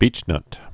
(bēchnŭt)